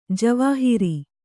♪ javāhiri